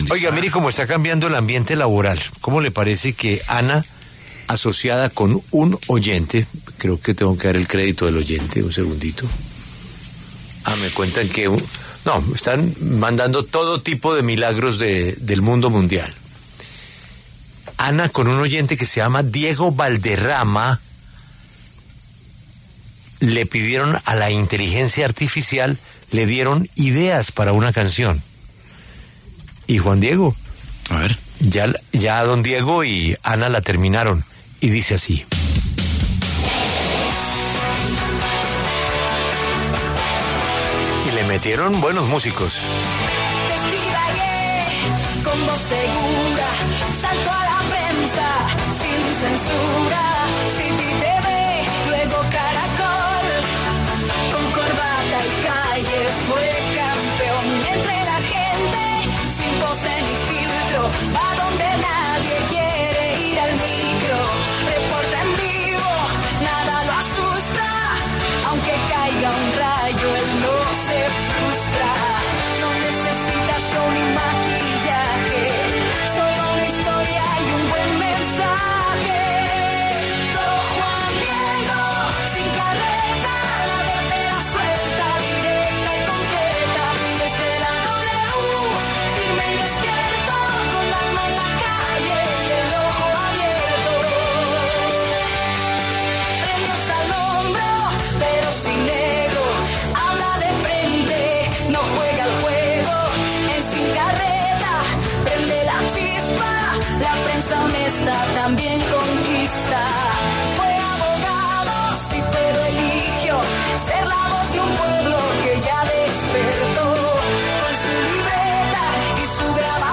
La canción se creó utilizando un generador de música a partir de inteligencia artificial.